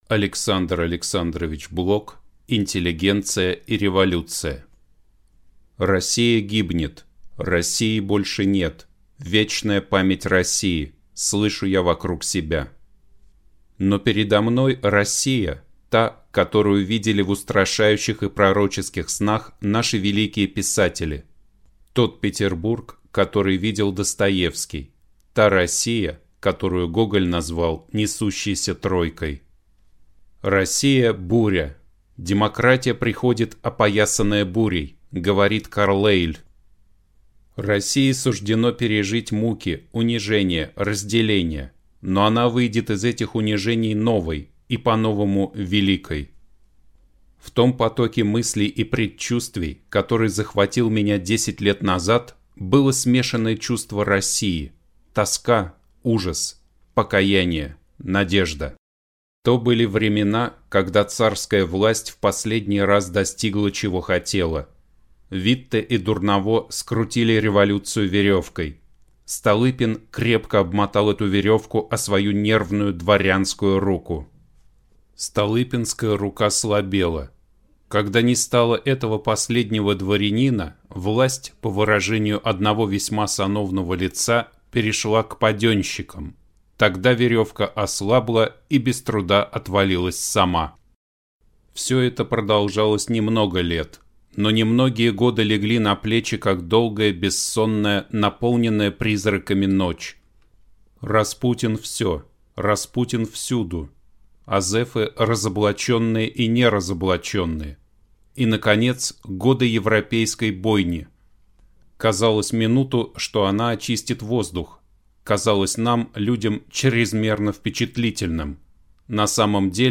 Аудиокнига Интеллигенция и Революция | Библиотека аудиокниг